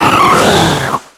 Cri d'Oniglali dans Pokémon X et Y.